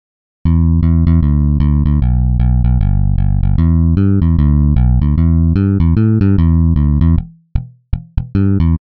We present a novel algorithm for automatic music synthesis based on a physical model of the electric bass guitar.
Rock riff (Deep Purple - Black Night) with different plucking styles:
Picked (PK)